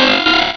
cries
wingull.aif